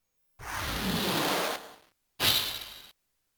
cdr final fight intro noise.mp3